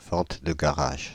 Ääntäminen
Synonyymit braderie vide-greniers vide-grenier Ääntäminen Paris: IPA: [vɑ̃t də ɡa.ʁaʒ] Tuntematon aksentti: IPA: /vɑ̃t.də ɡa.ʁaʒ/ Haettu sana löytyi näillä lähdekielillä: ranska Käännöksiä ei löytynyt valitulle kohdekielelle.